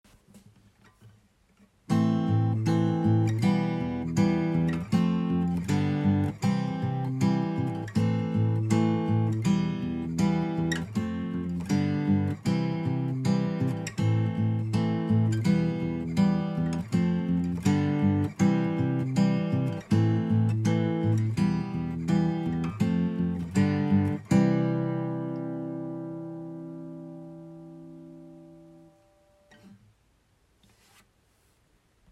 これにスピッツさんの『チェリー』のサビを乗せるといい感じです。
さらに、　GReeeeNさんの『キセキ』のサビをのせてもいい感じになります。